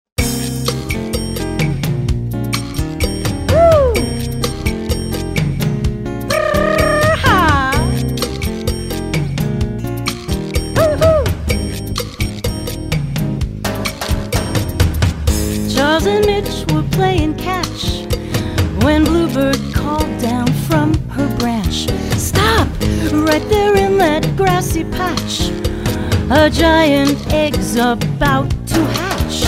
Vocal and